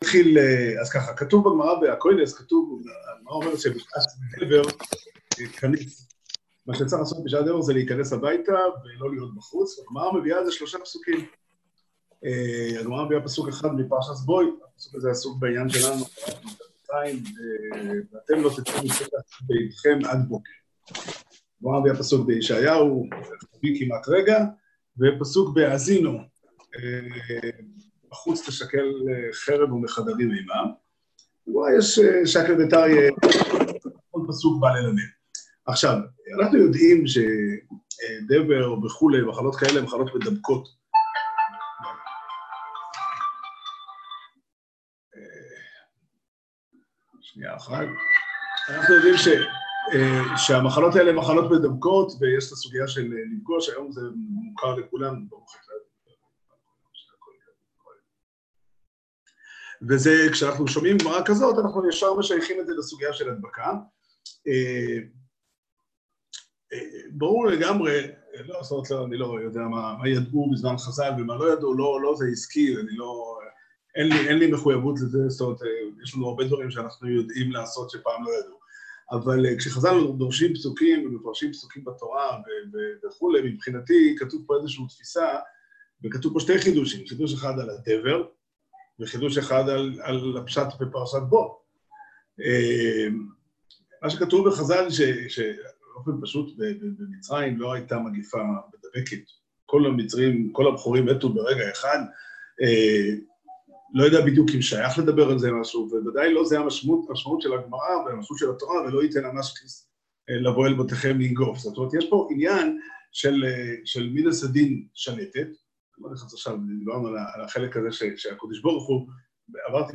שיעור שנמסר בזום בתקופת הקורונה בתאריך י"ב ניסן תש"פ